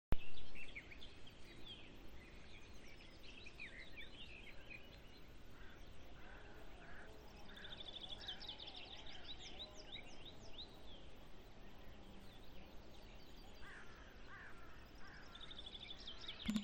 Birds -> Doves ->
Turtle Dove, Streptopelia turtur
StatusSinging male in breeding season